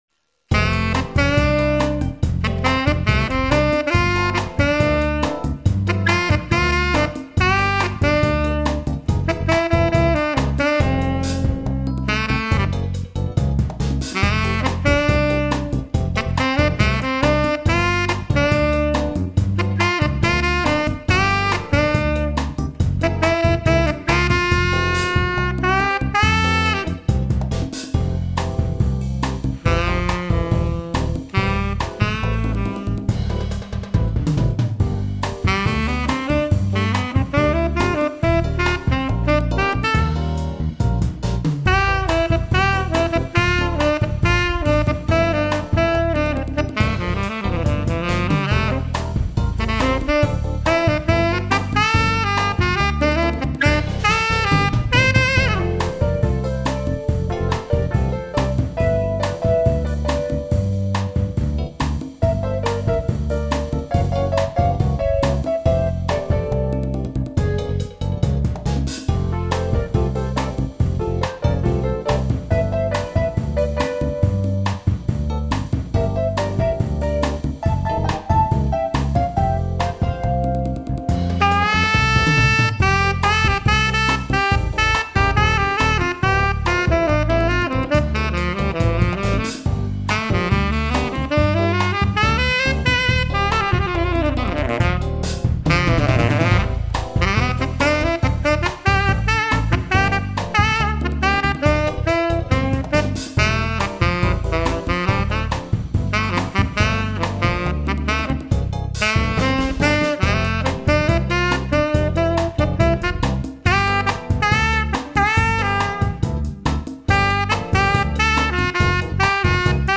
爵士即兴大碟
魅力爵士
物与魂的相撞和谐了爵士的旋律。